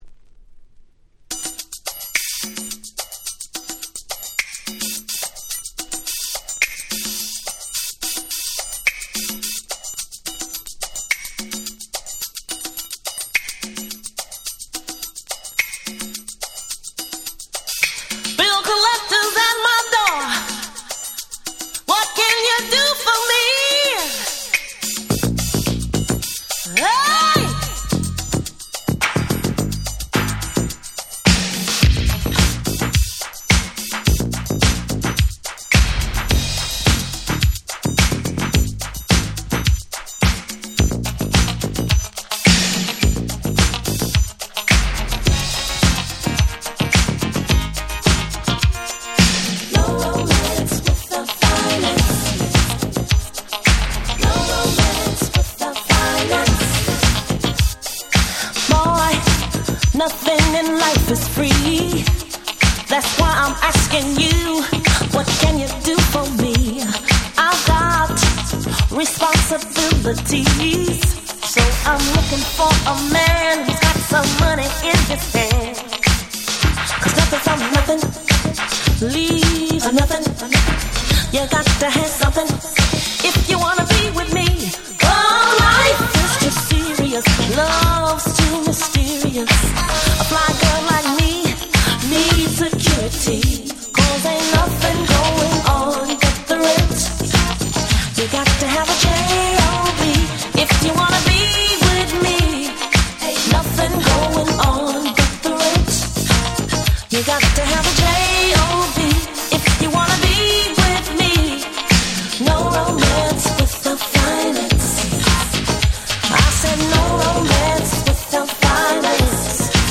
86' Smash Hit Disco / Boogie !!
込み上げて来る様なVocalが堪りません！！